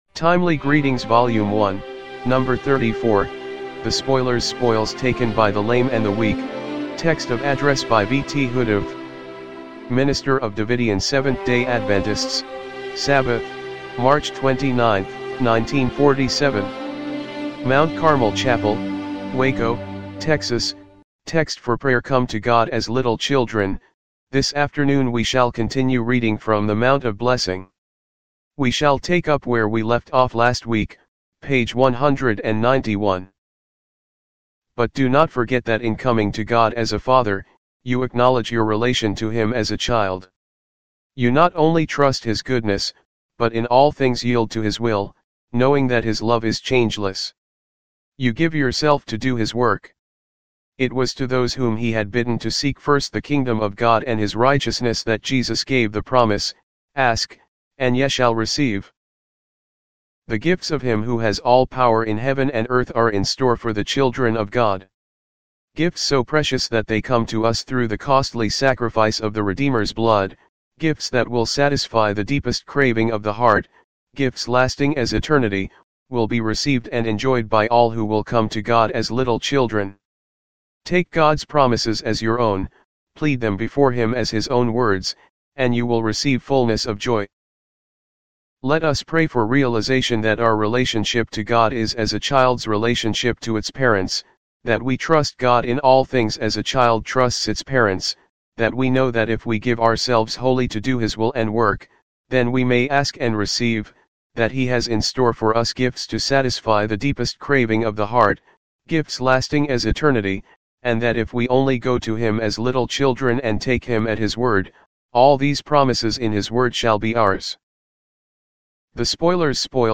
1947 MT. CARMEL CHAPEL WACO, TEXAS